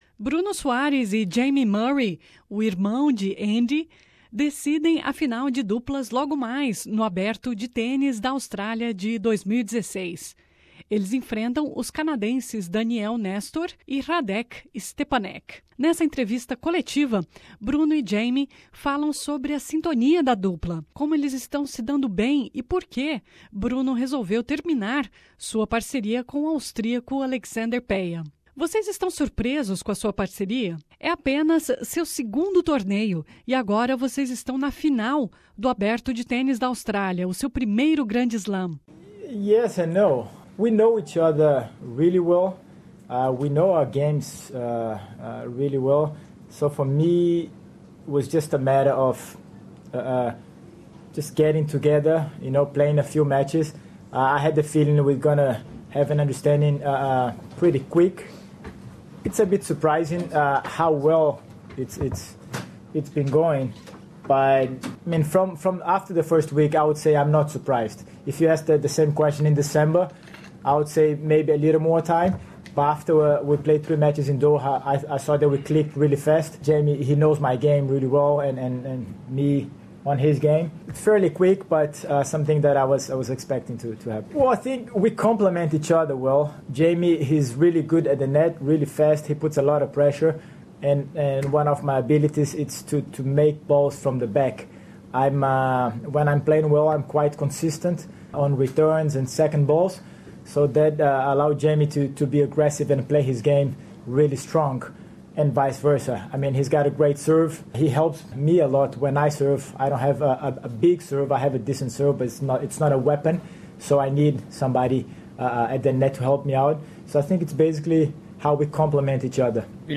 Ouça as entrevistas, em inglês, com Bruno e Jamie sobre a sintonia da dupla (esse é apenas o segundo torneio), a expectativa diante da final e o motivo pelo qual Bruno resolveu terminar sua parceria com o austríaco Alexander Peya e aceitar o convite para jogar com Jamie. Bruno Soares e Jamie, irmão de Andy Murray, decidem a final de duplas neste sábado à noite no Aberto de Tênis da Austrália.